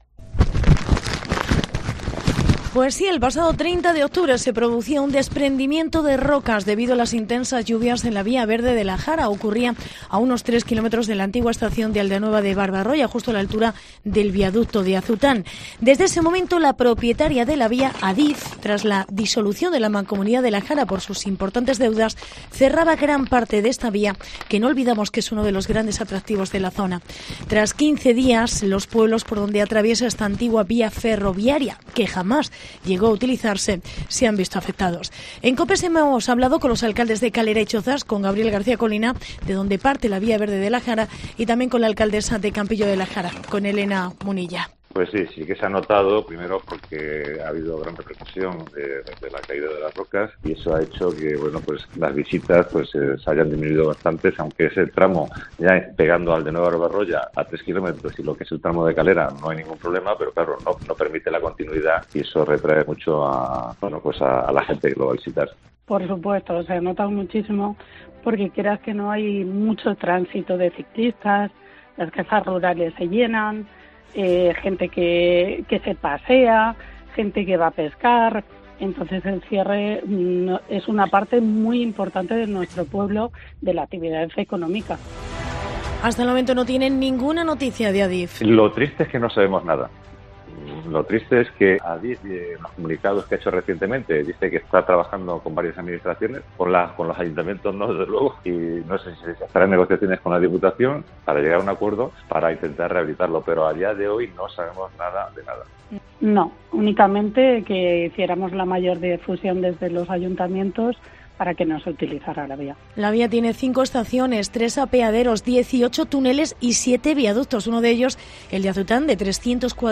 Reportaje cierre de la Vía Verde de la Jara